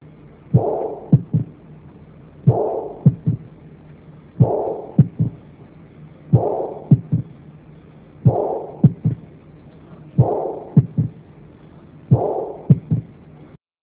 This Page contains some of the sounds of pathological Mitral and Aortic Valve Lesions.
MI and SM Plus S3-Sound